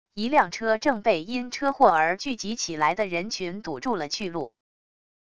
一辆车正被因车祸而聚集起来的人群堵住了去路wav音频